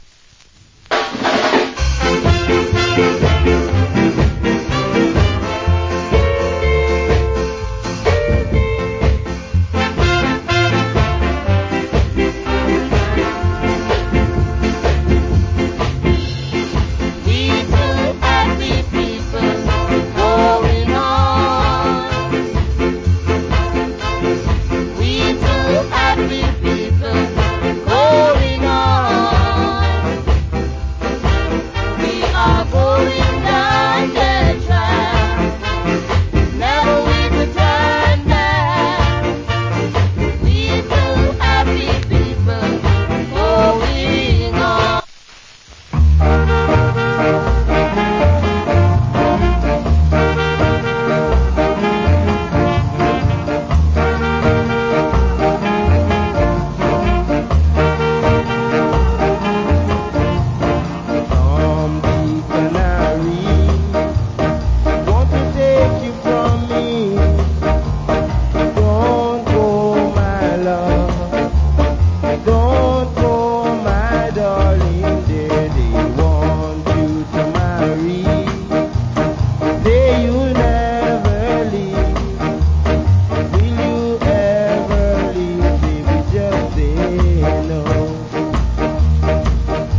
Wicked Duet Ska Vocal.